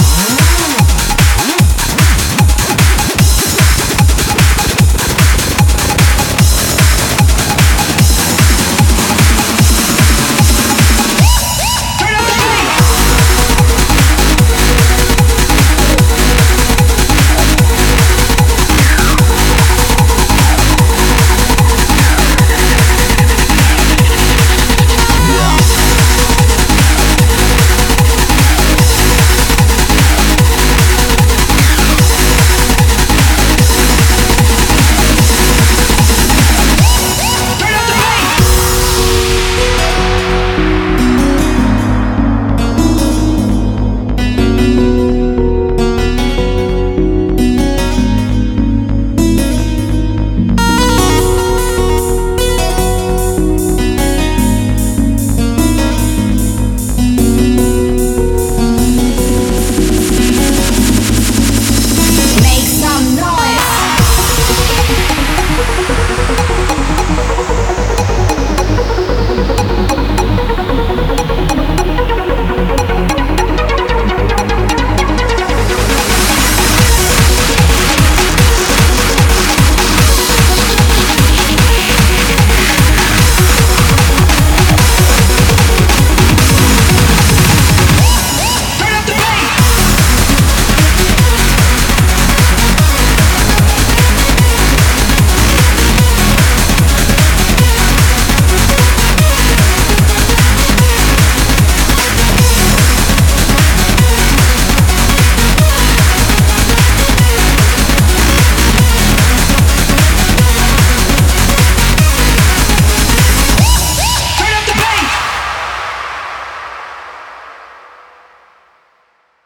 BPM150
Audio QualityPerfect (High Quality)
Comments[TECH DANCE]